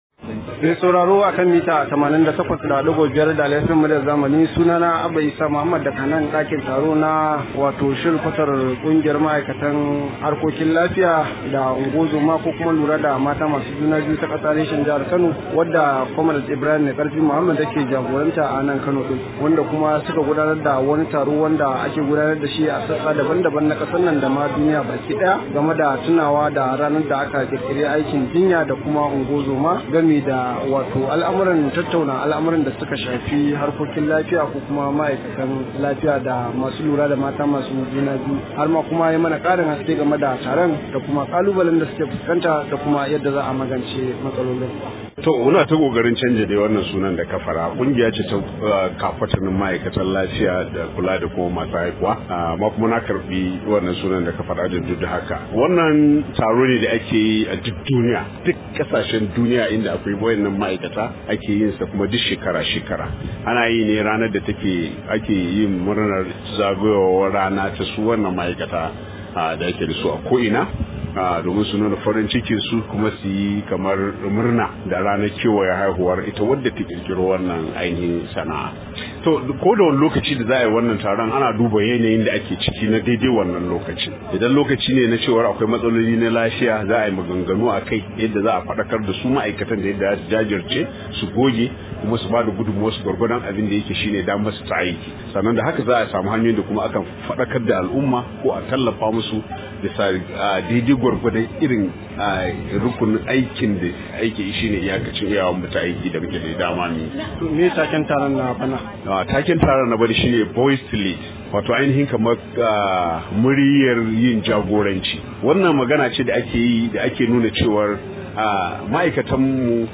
Rahoto: Rashin wadatattun ma’aikata ne kalubalen mu – Kungiyar ma’aikatan lafiya